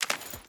Footsteps / Water / Water Chain Jump.wav
Water Chain Jump.wav